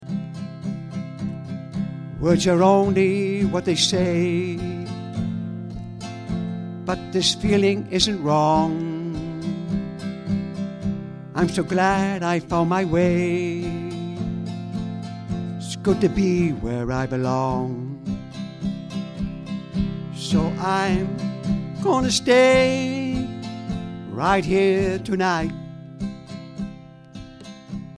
Ashington Folk Club - Spotlight 16 November 2006